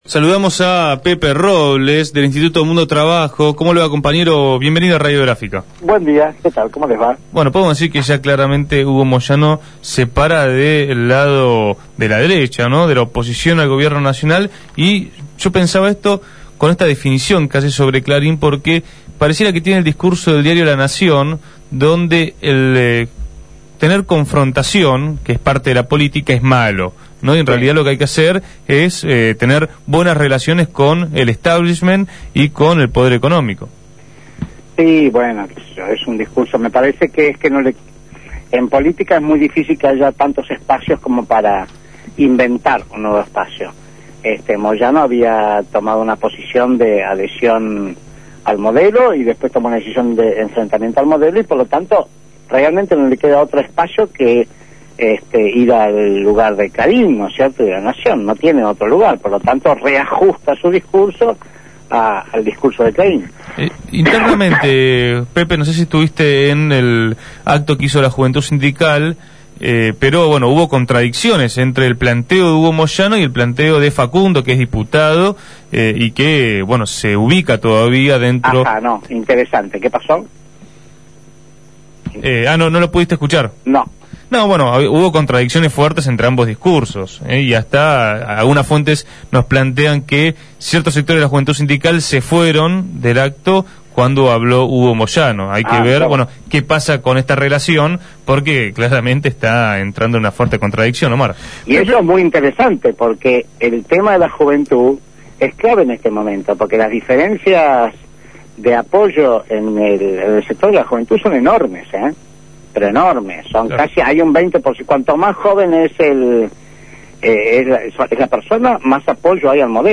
conversó en Punto de Partida sobre la actualidad de la Confederación General del Trabajo (CGT).